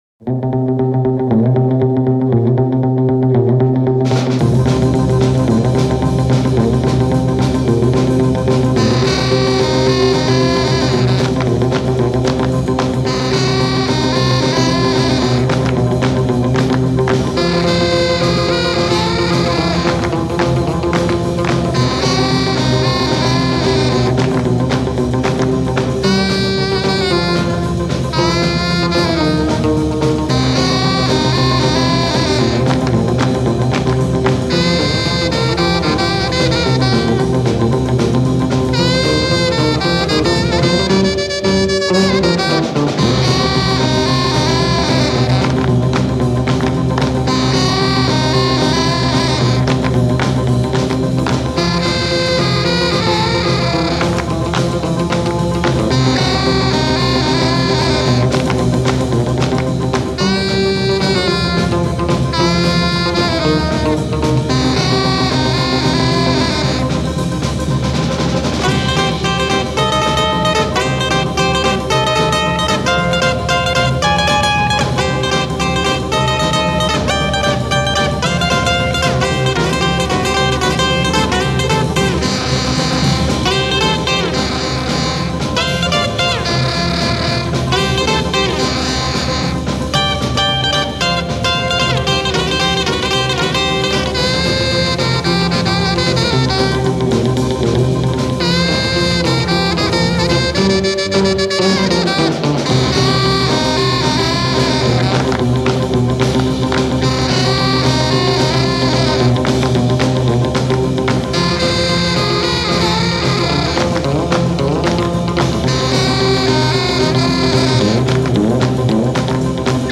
(Без вокала кстати вообще).Интересный поворот.